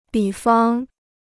比方 (bǐ fang): analogy; instance.